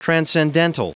Prononciation du mot transcendental en anglais (fichier audio)
Prononciation du mot : transcendental